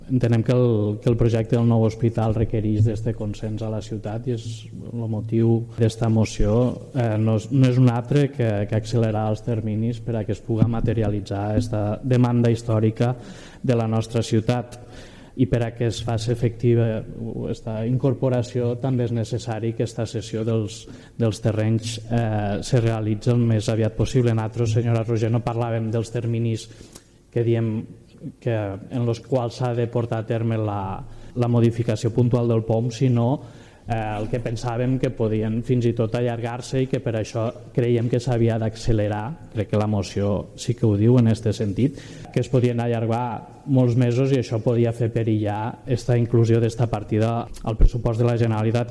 El portaveu de la CUP, Sergi Arnau, s’ha mostrat satisfet del consens assolit pels grups polítics…